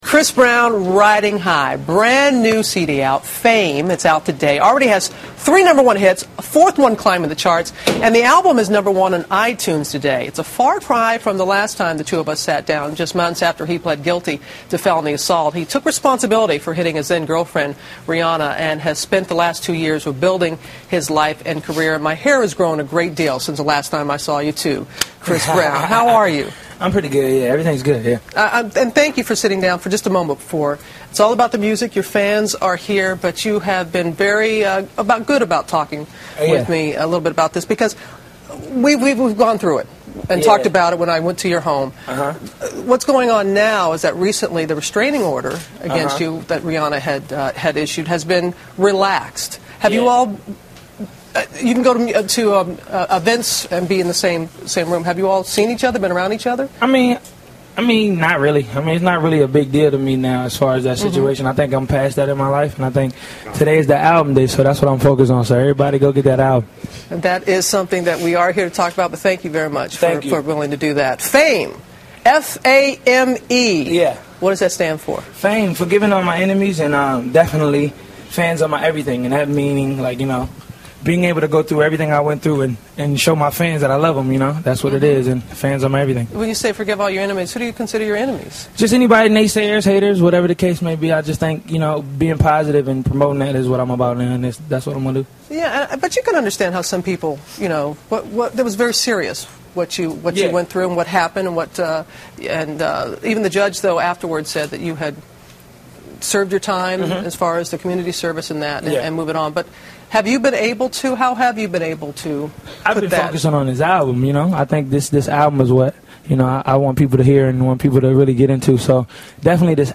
访谈录 2011-03-26&03-28 嘻哈小天王克里斯布朗专访 听力文件下载—在线英语听力室